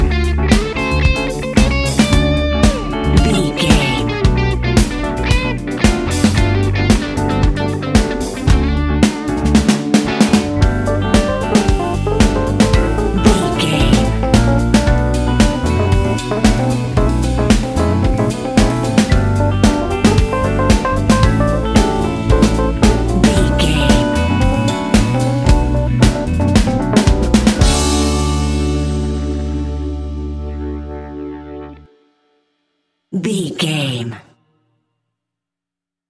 Ionian/Major
D♭
house
electro dance
synths
techno
trance